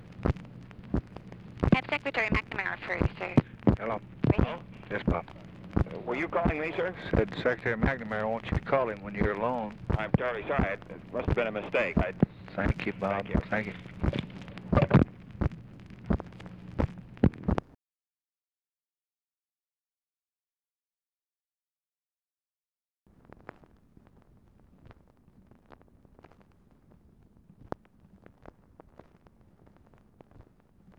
Conversation with ROBERT MCNAMARA, November 26, 1963
Secret White House Tapes